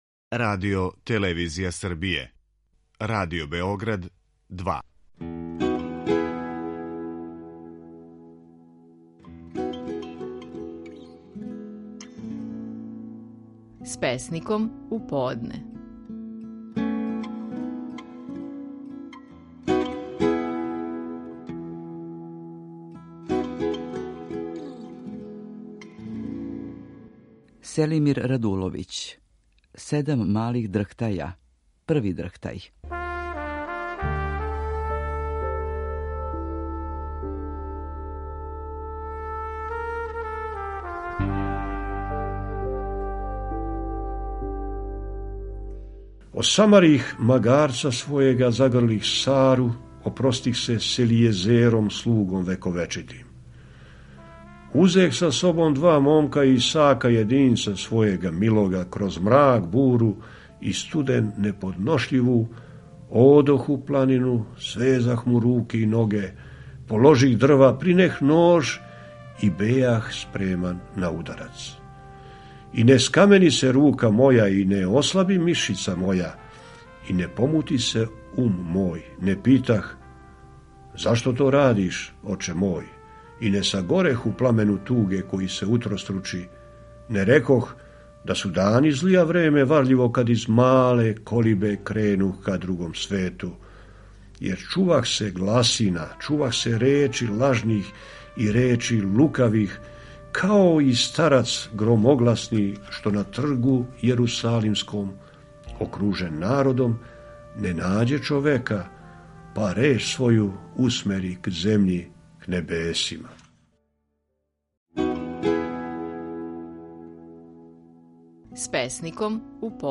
Стихови наших најпознатијих песника, у интерпретацији аутора.
Селимир Радуловић говори песму „Седам малих дрхтаја: Први дрхтај".